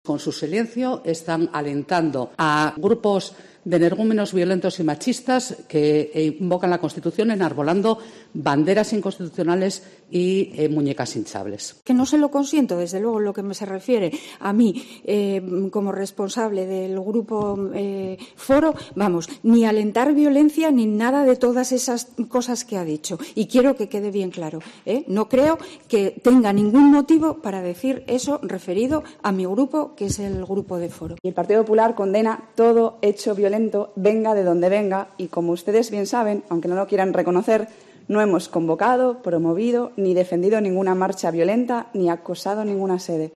Tenso debate en Gijón por la ley de amnistía: Pineda (PSOE), Moriyón (Foro) y Pumariega (PP)